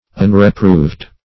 Search Result for " unreproved" : The Collaborative International Dictionary of English v.0.48: Unreproved \Un`re*proved\, a. 1.